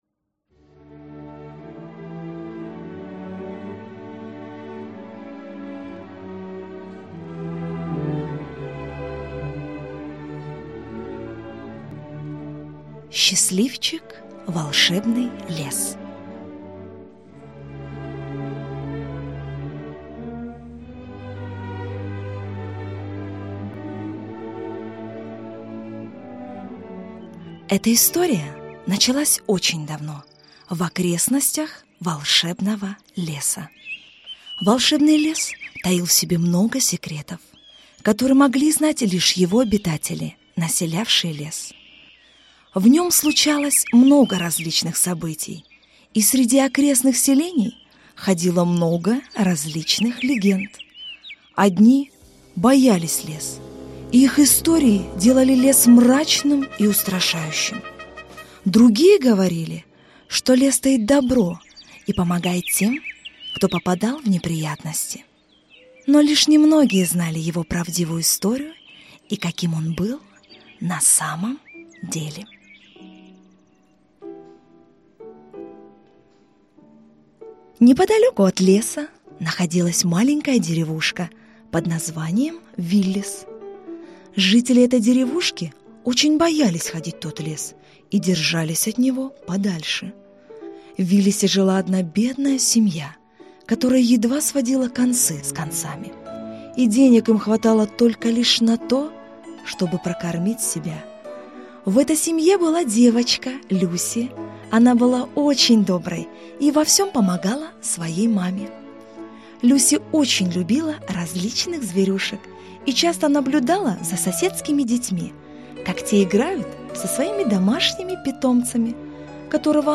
Аудиокнига Счастливчик. Волшебный лес | Библиотека аудиокниг